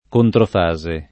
controfase [ kontrof #@ e ] s. f. (fis.)